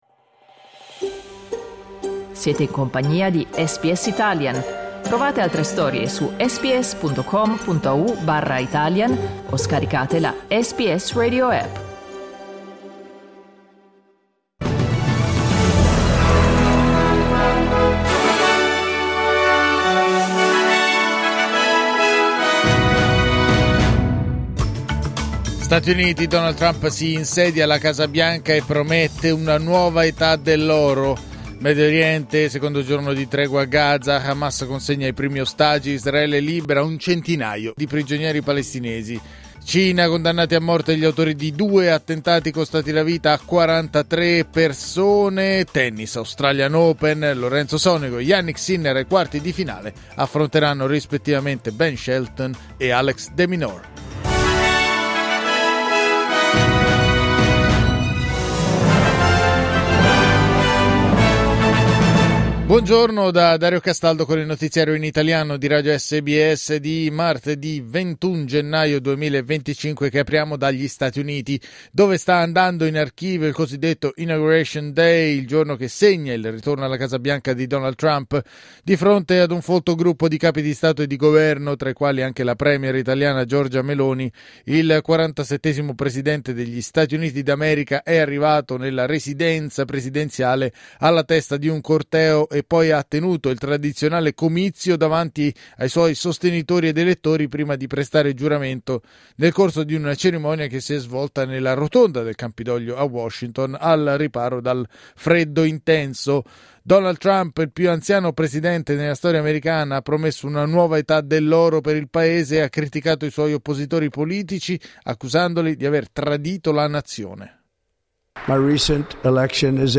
Giornale radio martedì 21 gennaio 2025
Il notiziario di SBS in italiano.